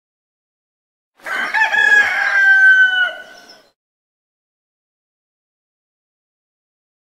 دانلود صدای خروس 2 از ساعد نیوز با لینک مستقیم و کیفیت بالا
جلوه های صوتی